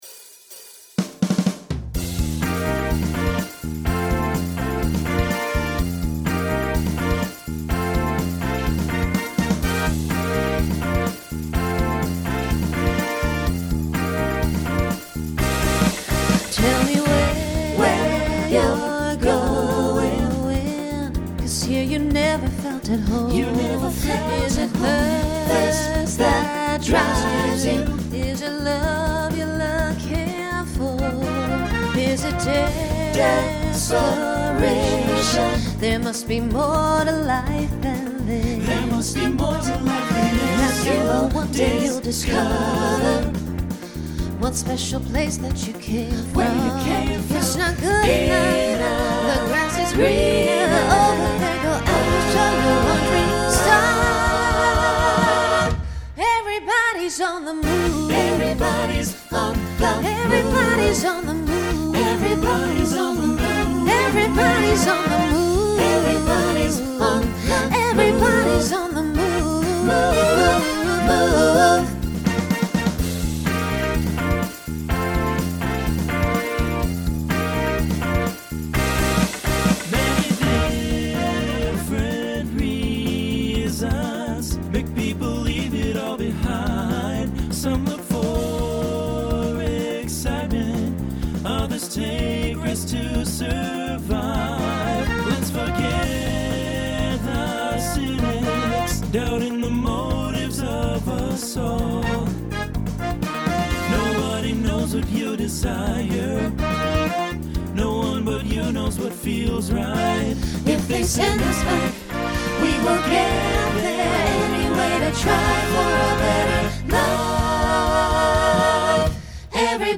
Starts with a quintet, then TTB, then SATB.